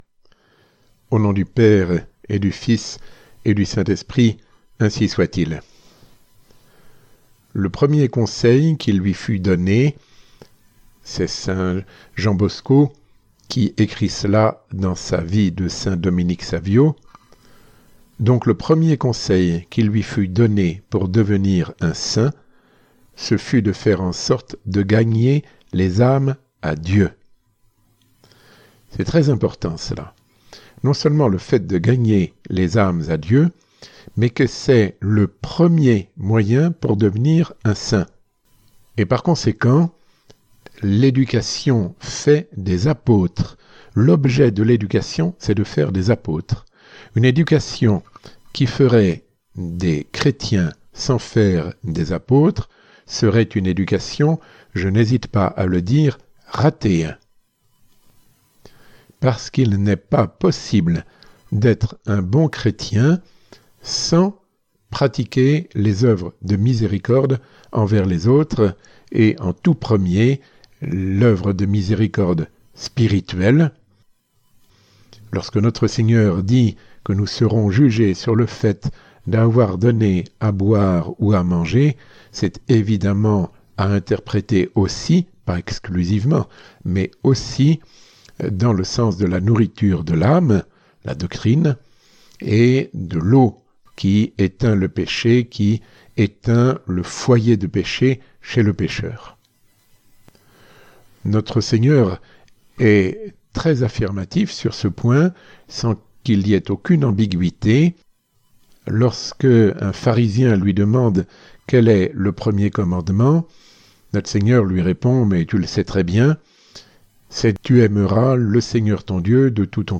Résumé du sermon